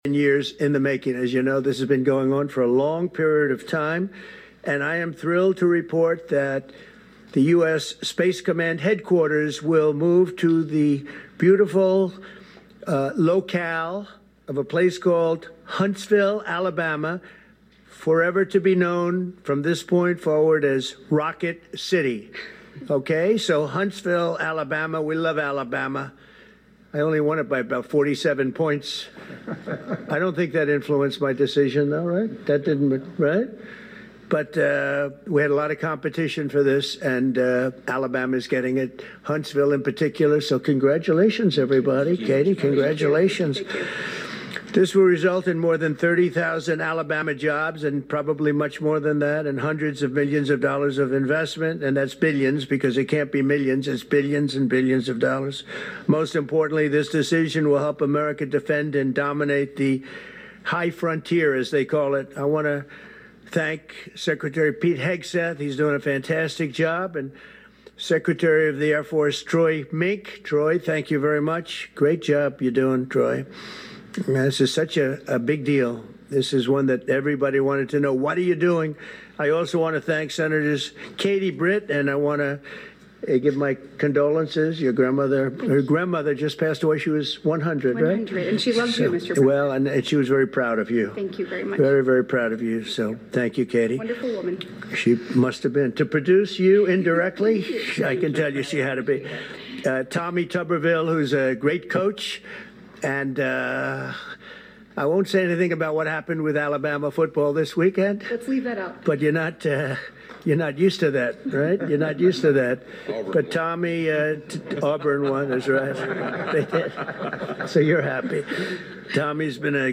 President Donald Trump announced this afternoon that U.S. Space Command will be moving to Huntsville from Colorado Springs. Audio of President Trumps press conference in the audio link below.